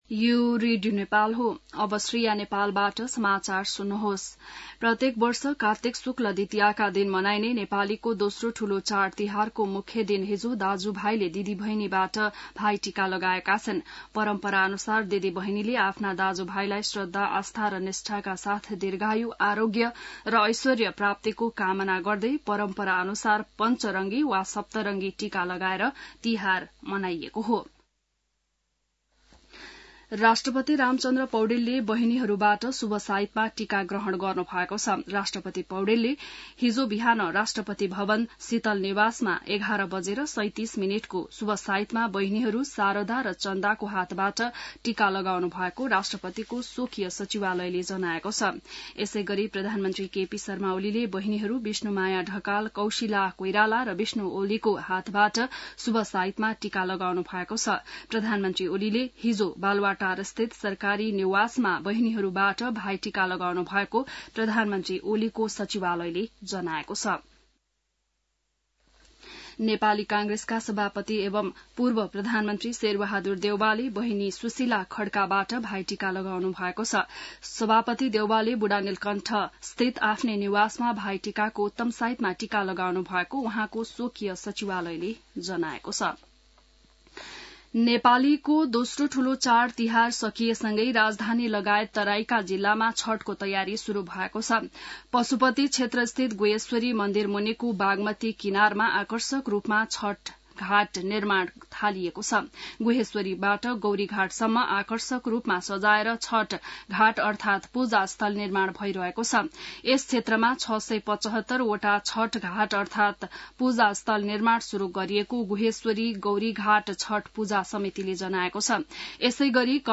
बिहान ६ बजेको नेपाली समाचार : २० कार्तिक , २०८१